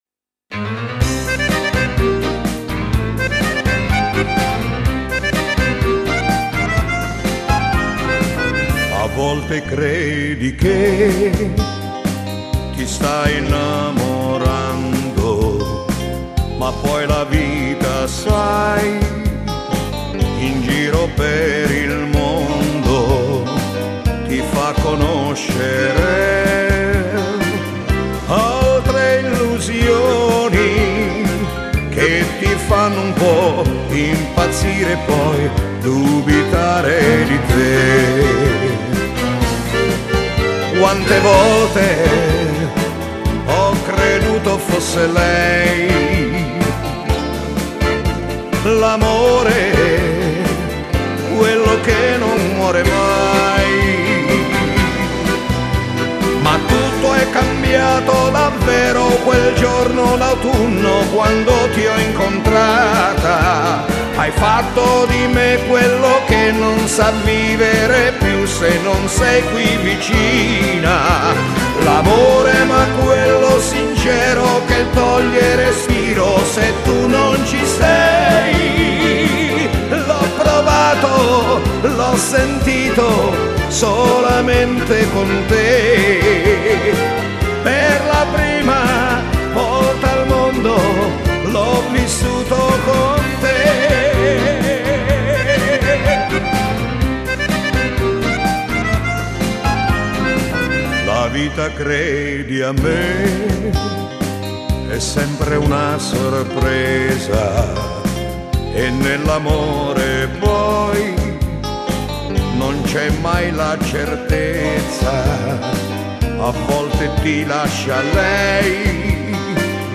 Genere: Beguine